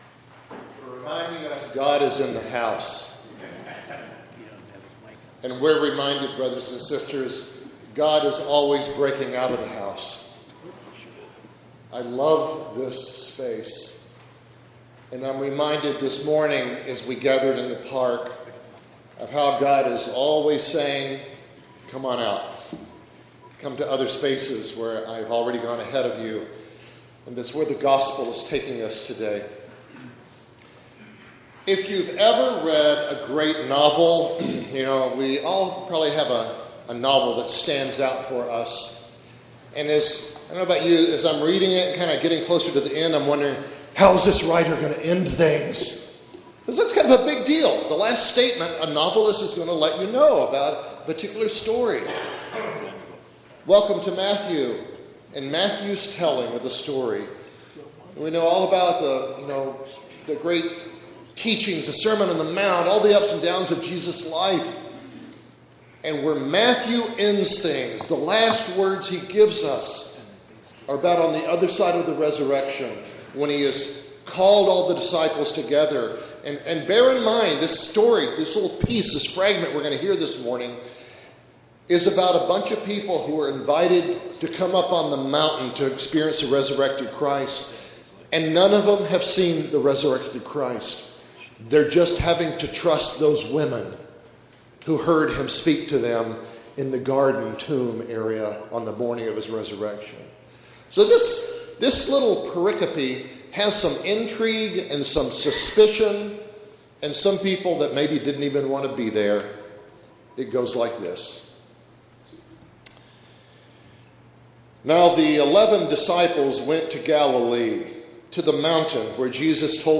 50th Anniversary Church in the Park Celebration Worship